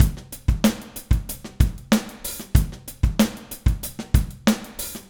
Trem Trance Drums 02.wav